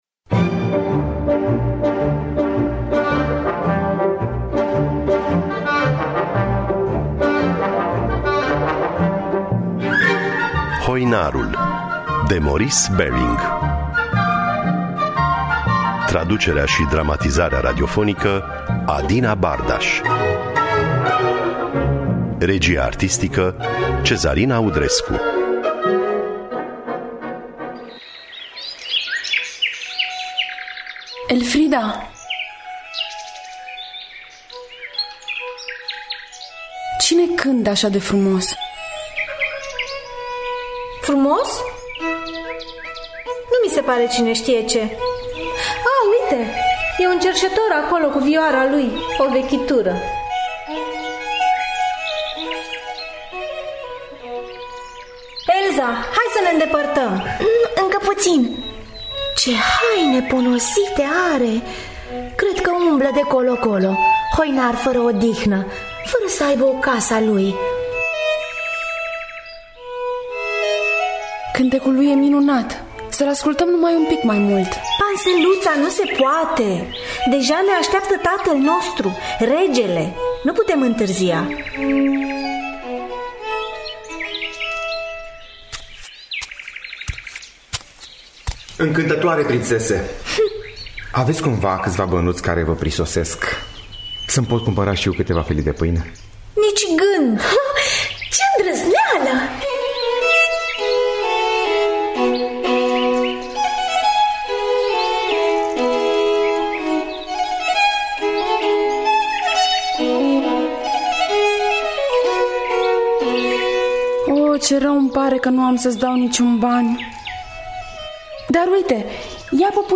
Hoinarul de Maurice Baring – Teatru Radiofonic Online